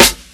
CDK Lover Snare.wav